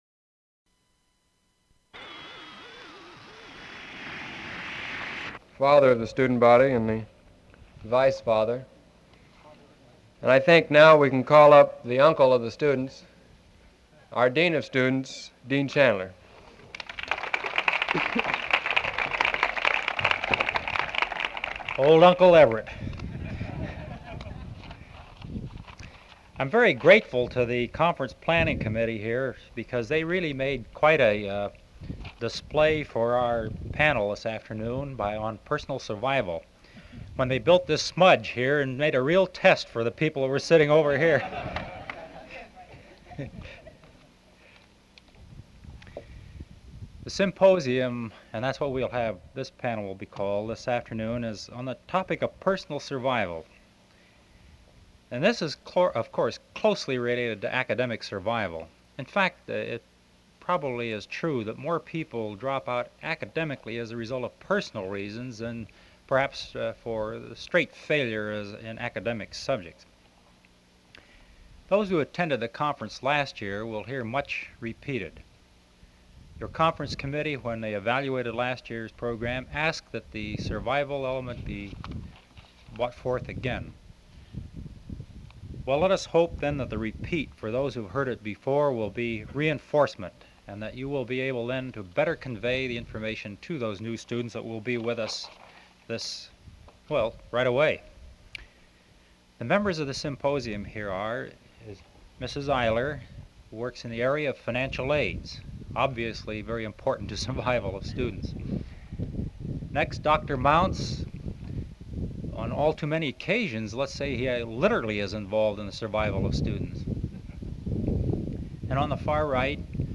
Summit Conference, 1967 [reels 1 and 2, and program]